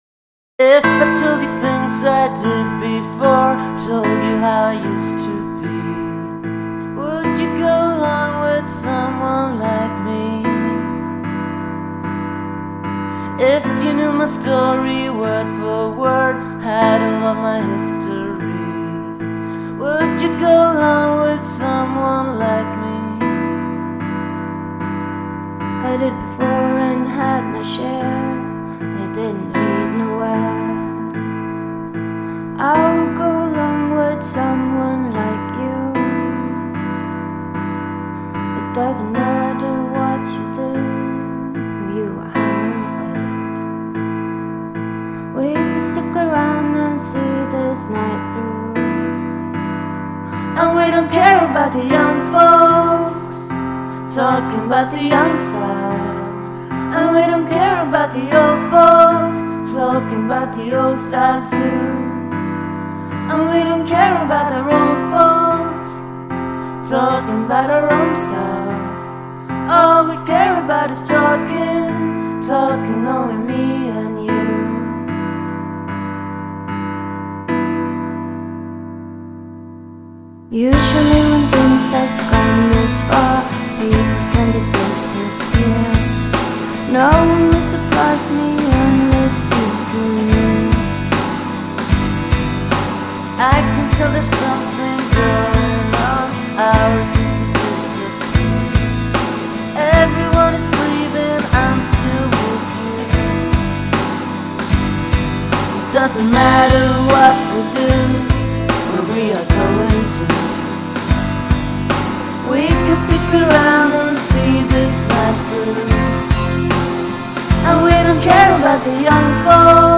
Bootlegs (page 12):
Instru
Acappella .